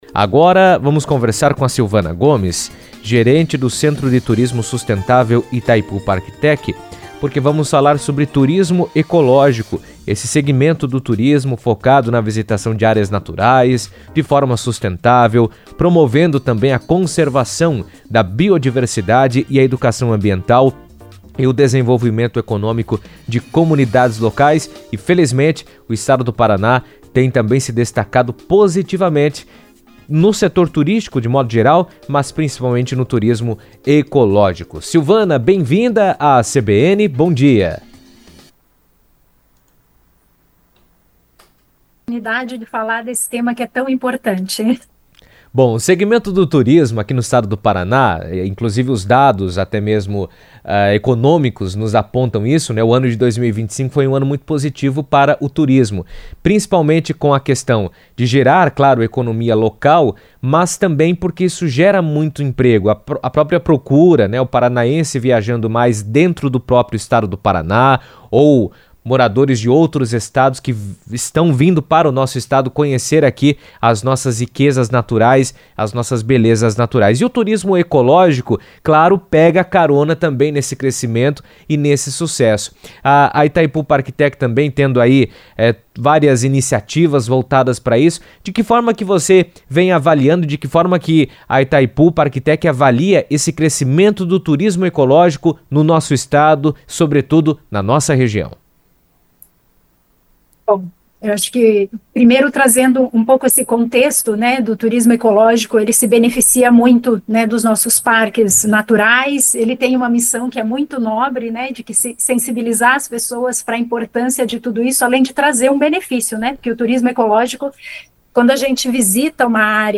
O turismo ecológico tem ganhado cada vez mais destaque como uma alternativa de viagem que alia lazer, preservação ambiental e desenvolvimento econômico. A proposta do segmento é incentivar a visitação de áreas naturais de forma responsável, valorizando a biodiversidade, promovendo educação ambiental e gerando renda para comunidades locais. Em entrevista à CBN